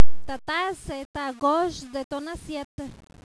(le dialogue)